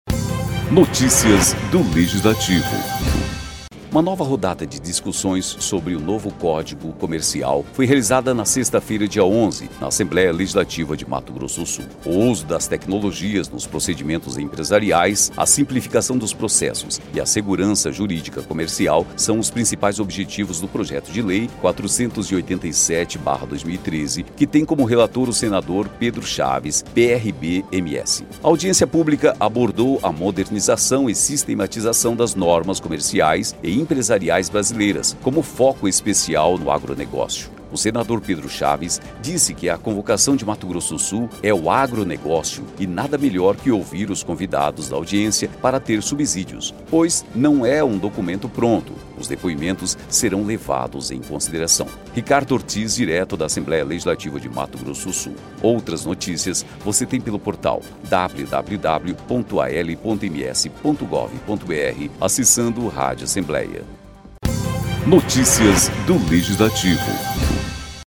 Audiência debate desburocratização e uso da tecnologia nas relações comerciais